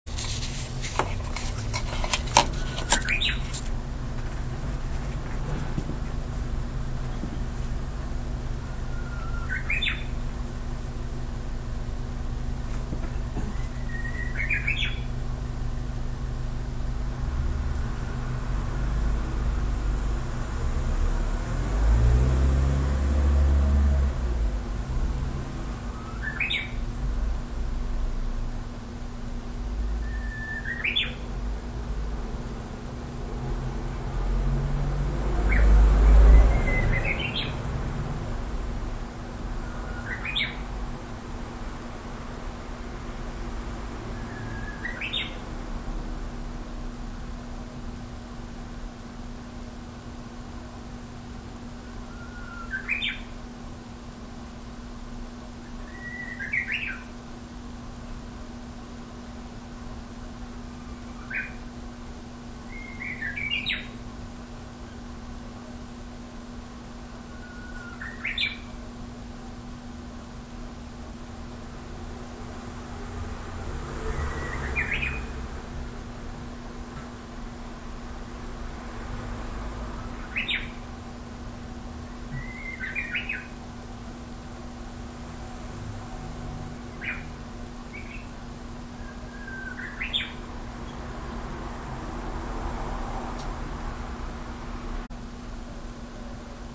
ところで、表題とは全く関係ありませんが、ウグイスのさえずりが録音できましたので、今回はそれをお届けします。
録音機材：三洋電機製ICレコーダー（ICR-s250rm）
このウグイス、私の職場の裏庭で、こっそりさえずる練習をしていました。
だって、こんなに近くでウグイスがさえずっていたのは初めてでしたので、急いでICレコーダーをセットしました。
まだちょっと下手くそなさえずりかなって思いますけど、その後も２・３日はきれいな鳴き声で楽しませてくれました。
ご存じのように、ウグイスは「ホーホケキョ」ときれいな声で楽しませてくれます。
ic_uguisu.mp3